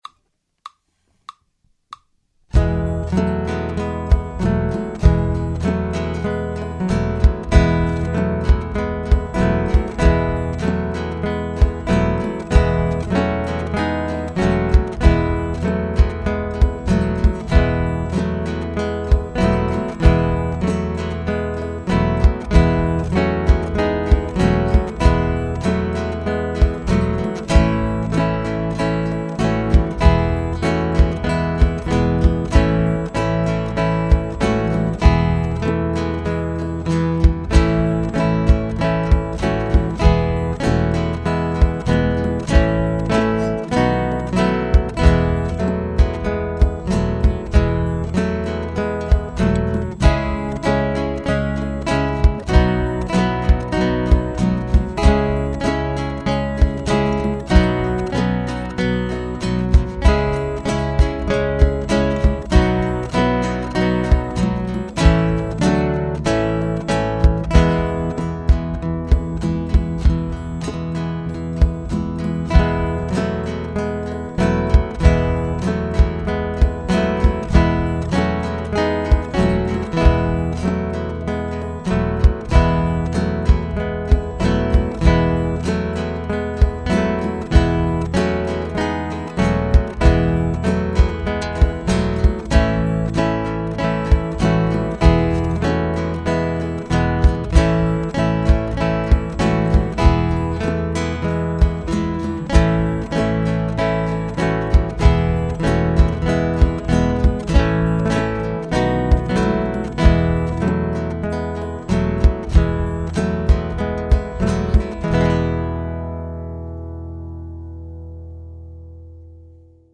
guitar ensembles in a variety of styles